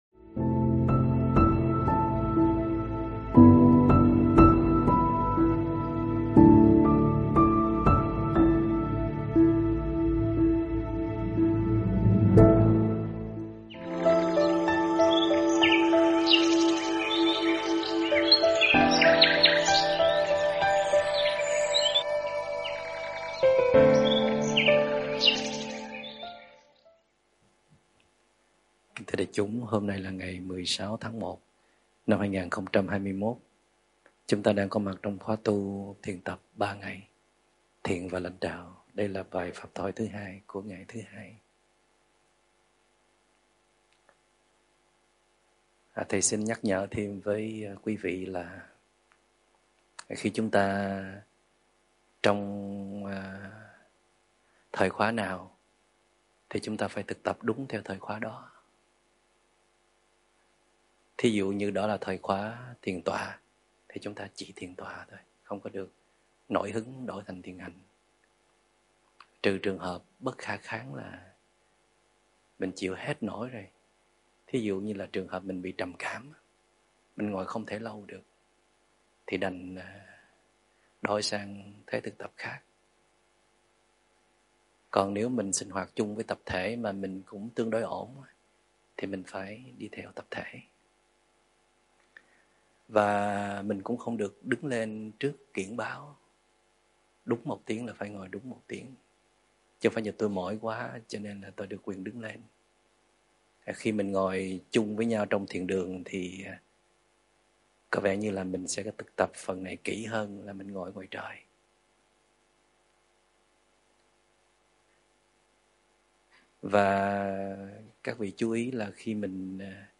thuyết pháp Đã đủ quyết tâm để quay về phát triển nội lực chưa
giảng trong khóa Thiền & Lãnh Đạo